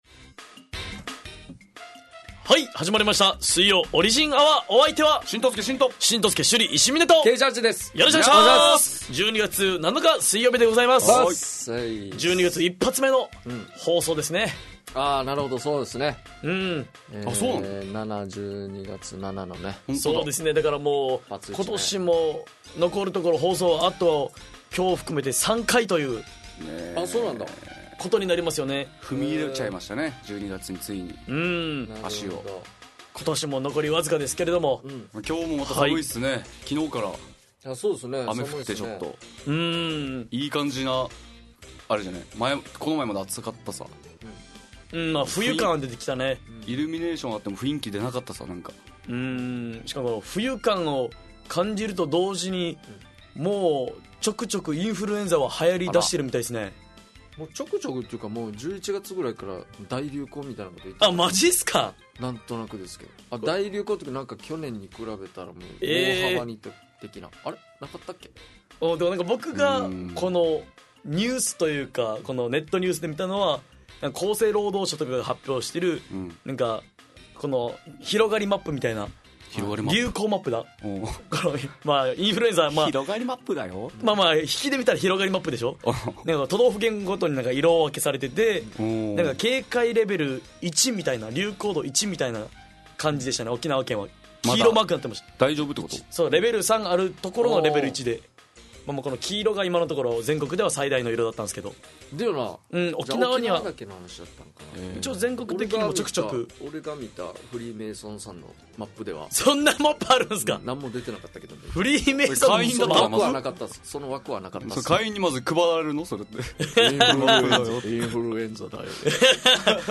fm那覇がお届けする沖縄のお笑い集団オリジンと劇団O.Z.Eメンバー出演のバラエティ番組!